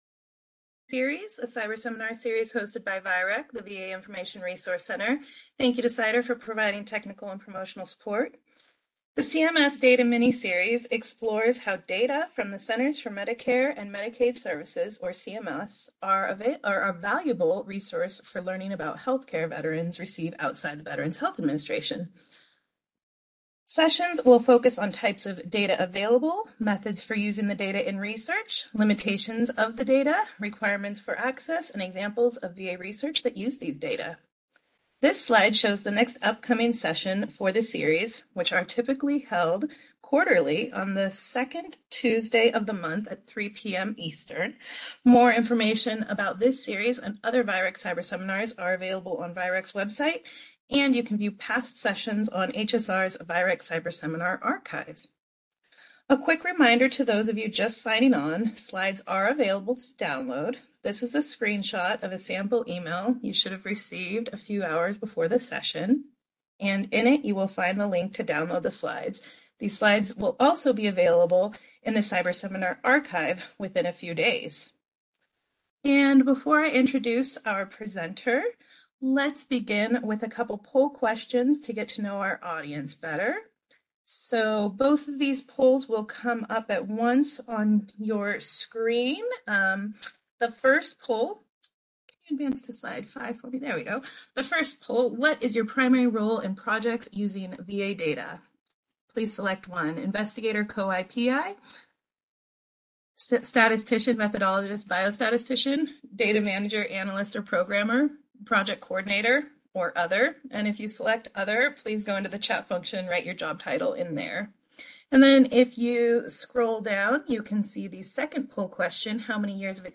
Description: This cyberseminar, part of the miniseries focused on data from the Centers for Medicare and Medicaid Services (CMS), will demonstrate how VA researchers can utilize CMS data when studying COVID-19 or COVID-19 vaccines. The presentation will include a brief overview of CMS data, statistics on the number of Veterans with COVID-19 and/or COVID-19 vaccines in the CMS data, and examples of ways the CMS data can be used to study COVID-19.